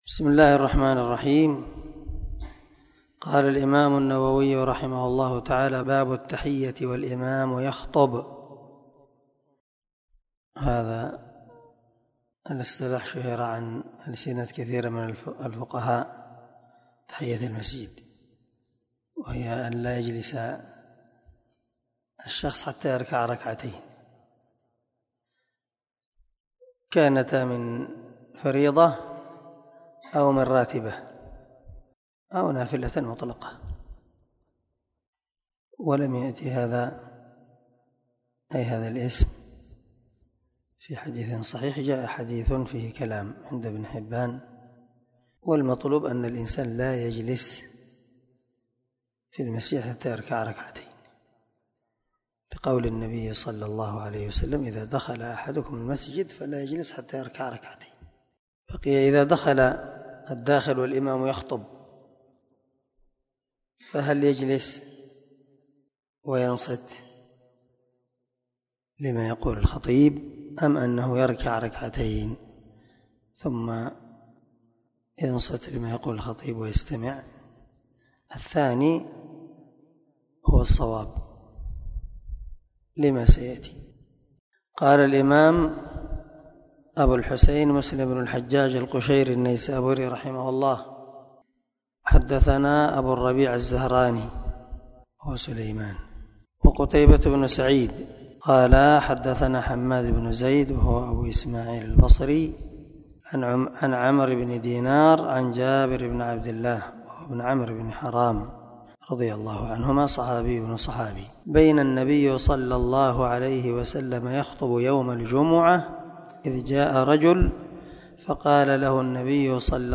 533الدرس 21 من شرح كتاب الجمعة الحديث رقم (875) من صحيح مسلم
دار الحديث- المَحاوِلة- الصبيحة.